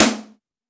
• Present Steel Snare Drum Sound A Key 350.wav
Royality free snare drum sound tuned to the A note. Loudest frequency: 3975Hz
present-steel-snare-drum-sound-a-key-350-Akt.wav